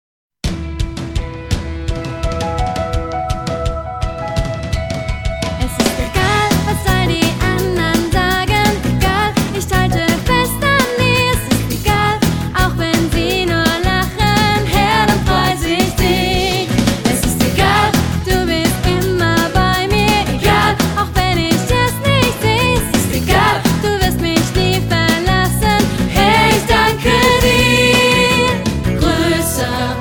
• Sachgebiet: Kinderlieder